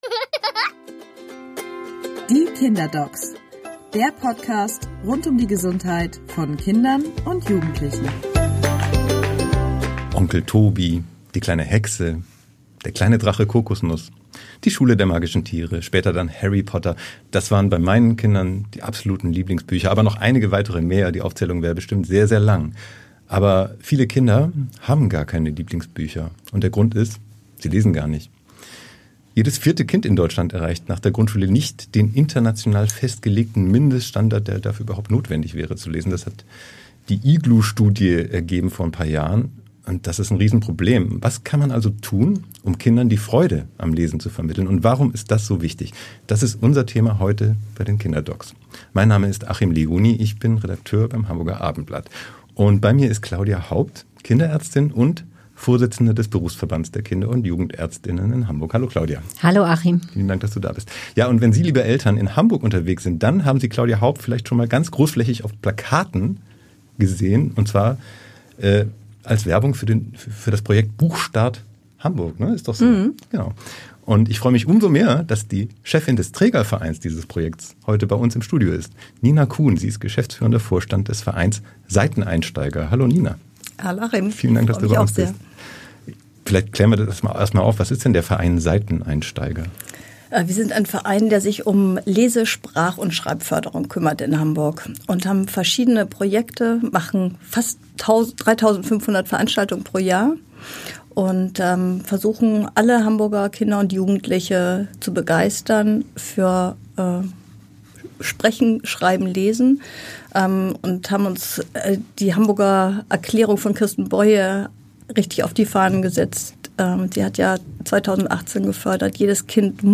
Bücher sind für die geistige und soziale Entwicklung auch in digitalen Zeiten unersetzlich. Zwei Expertinnen erklären, warum, und geben wertvolle Tipps.